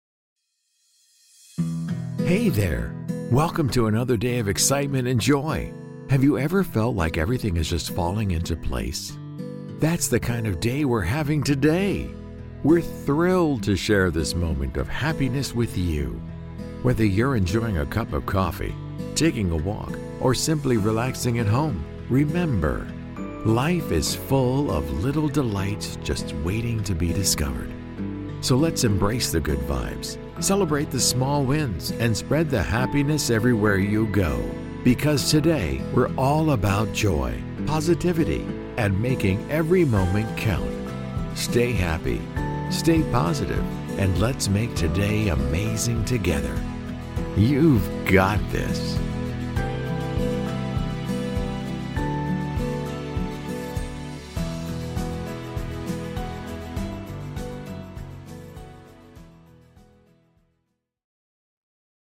Professional Voice Artist
English - USA and Canada
Middle Aged
Senior